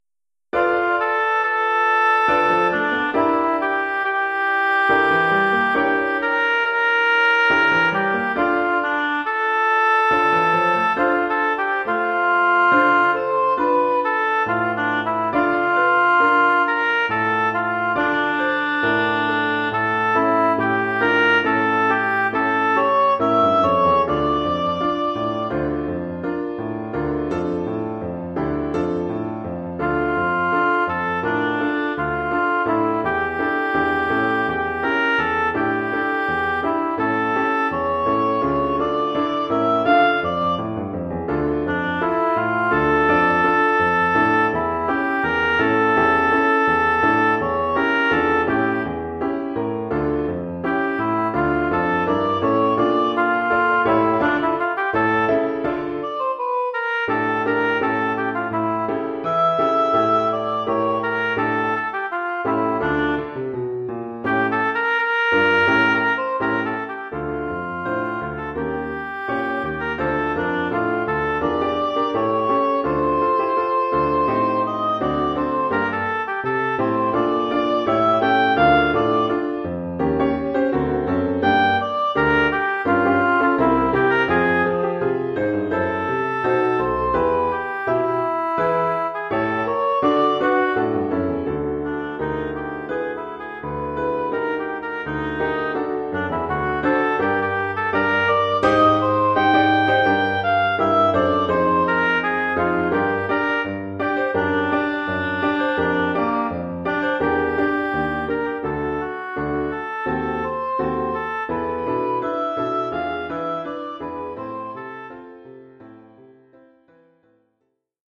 Oeuvre pour hautbois et piano.